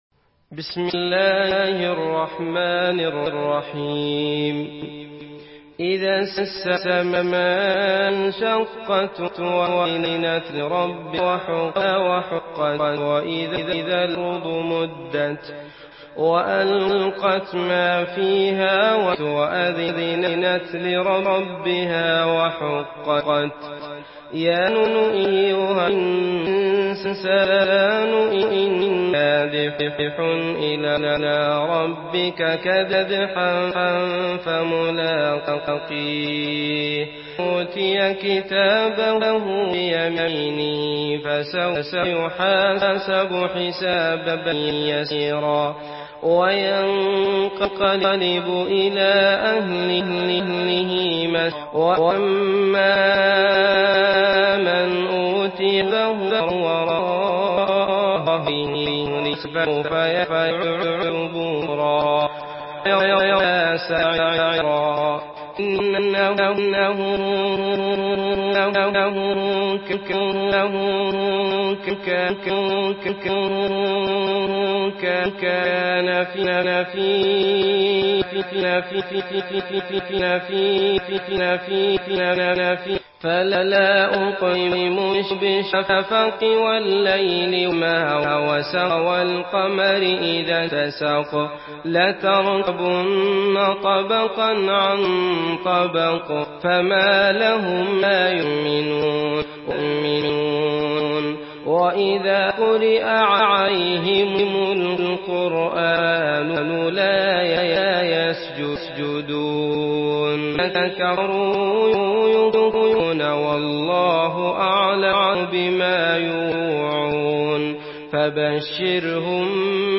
Surah Al-Inshiqaq MP3 by Abdullah Al Matrood in Hafs An Asim narration.
Murattal Hafs An Asim